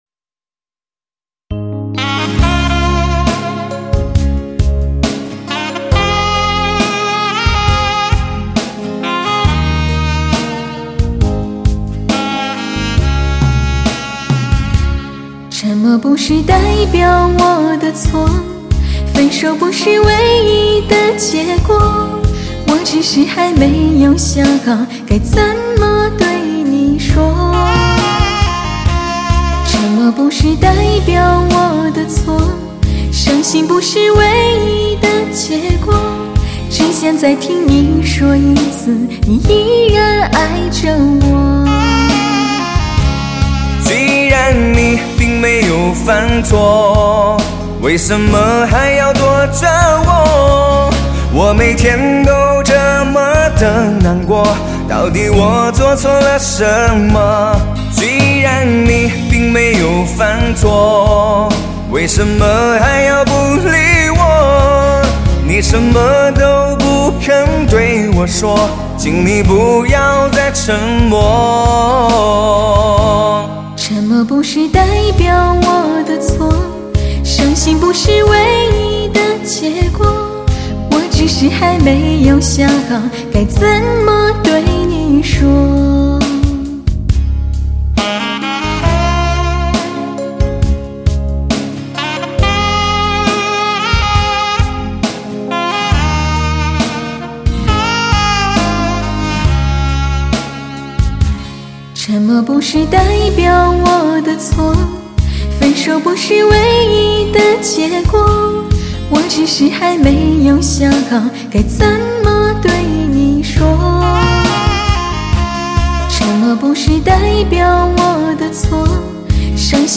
淳厚无暇的人声，似一剂至伤毒药，幽然痛彻心扉超一流的临场感，
倍具杀伤力的发烧录音，令人如饮酒酿般中毒沉醉。
音色更细腻准确、更有音乐味的特色；歌声的定位感、层次感、声音的透明度，人声喉音丰富的空气感，
真实鲜明的音乐质感，一切无可挑剔的音效表白，无论录音还是其音乐性都堪称至臻完美；